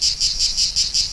chant_cigale.wav